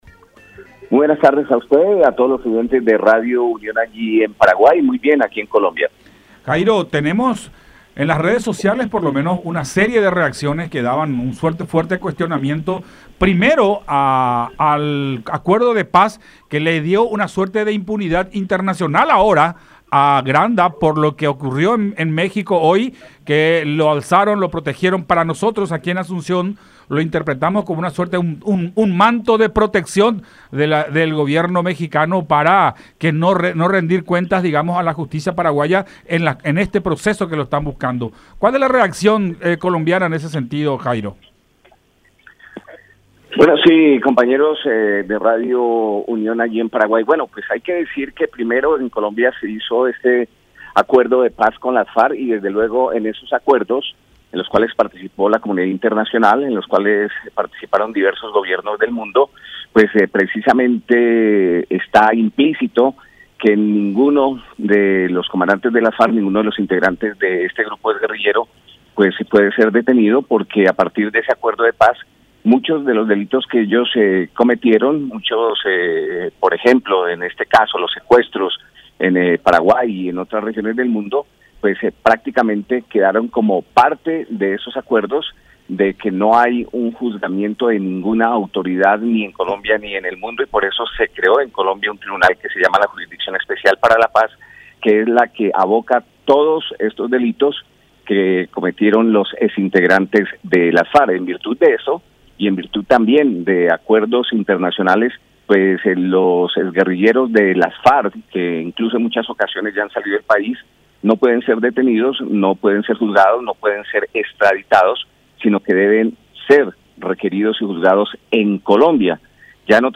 Así lo informó la Cancillería mexicana”, expuso el comunicador en conversación con Buenas Tardes La Unión.